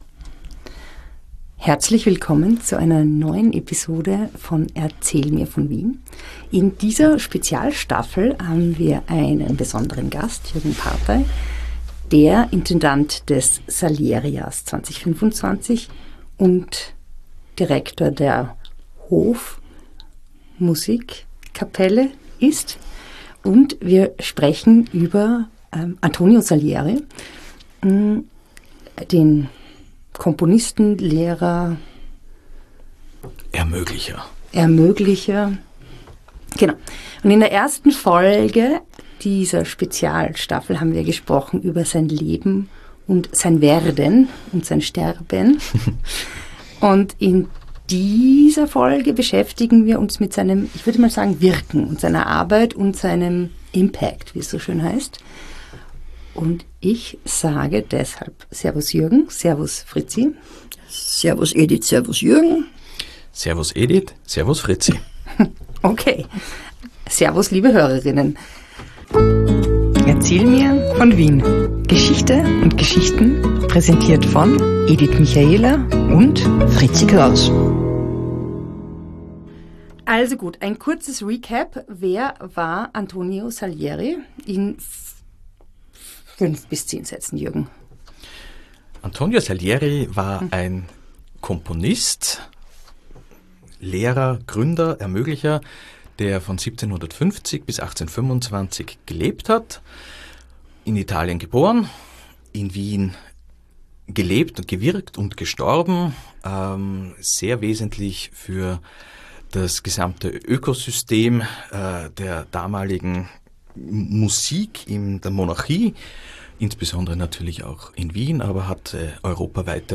Die beiden spazieren durch Wien und unterhalten sich über bekannte und unbekannte Orte, prägende Persönlichkeiten und die vielen kuriosen Geschichten, die es an allen Ecken, in allen Grätzeln und Bezirken Wien zu entdecken gibt.